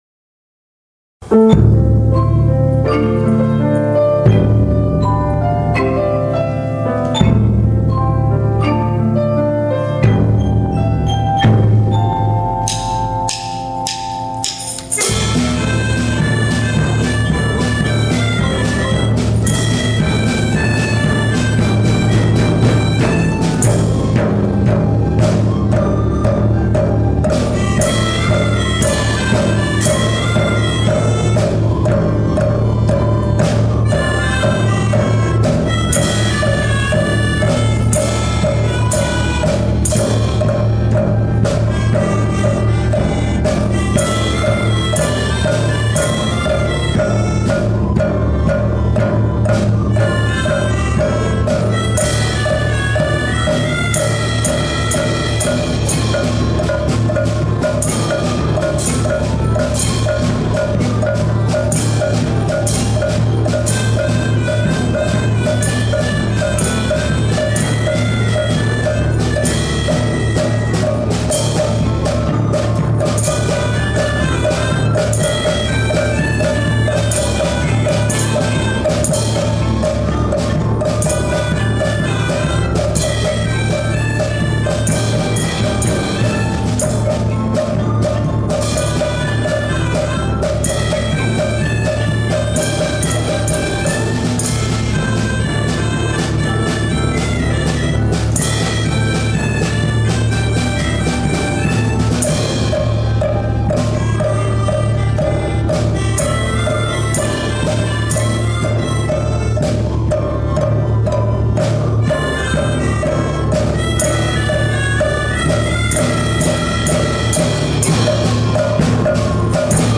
藤岡地区の小中学校から代表が集まって、合唱や合奏を発表し交流を深めました。
どの学校の曲もすばらしく、さわやかな秋の風のように、とても心地よい音楽会となりました。